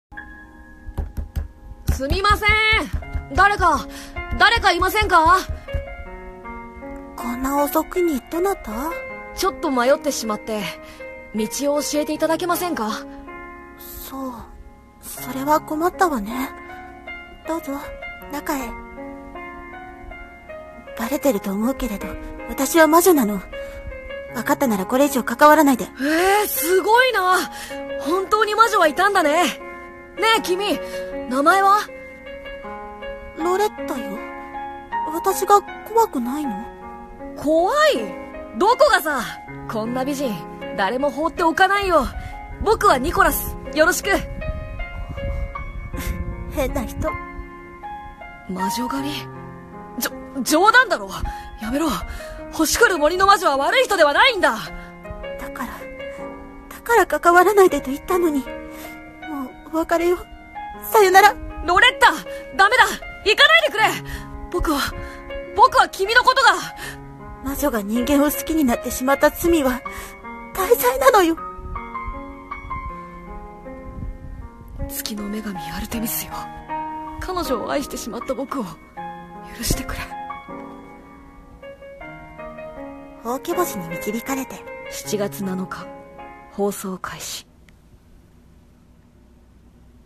CM風声劇『ほうき星に導かれて』